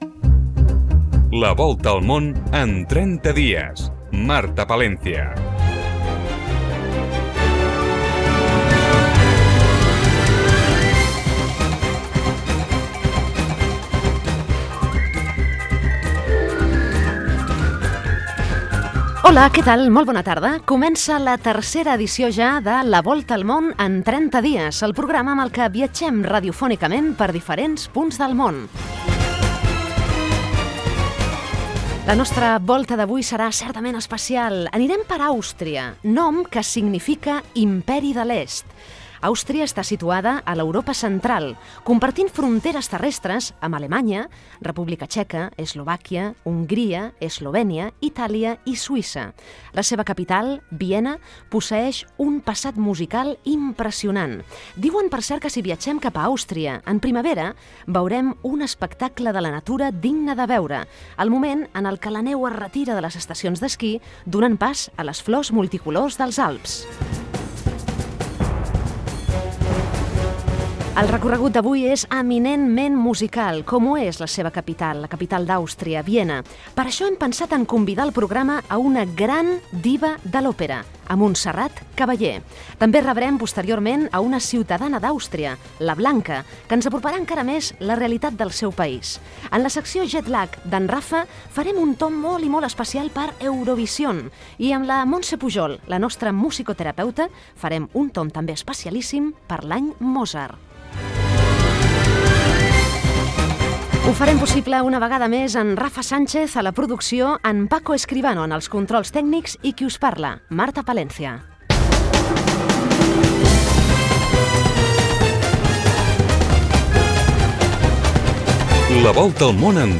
Indicatiu del programa, presentació de l'espai dedicat a Àustria, sumari, crèdits del programa, indicatiu , secció "Sabies que...". Fragment d'una entrevista a la soprano Montserrat Caballé.
Divulgació